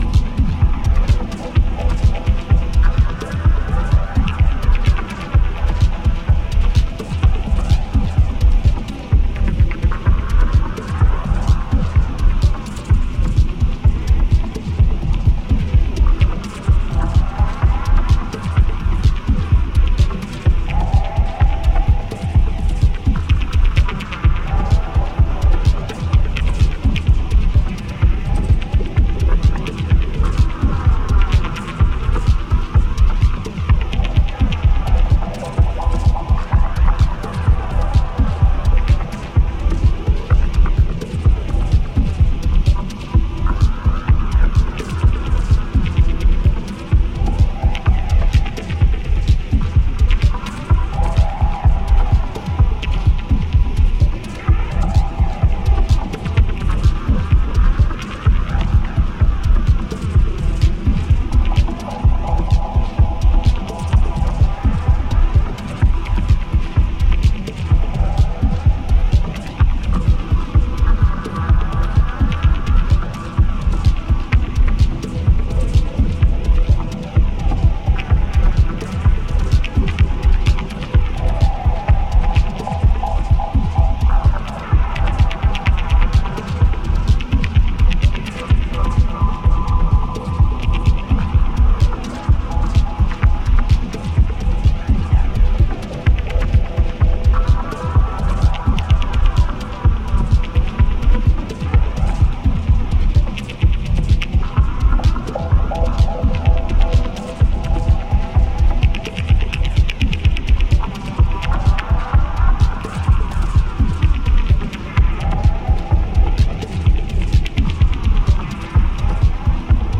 New Release Dub Techno Techno